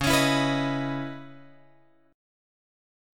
C# Minor 9th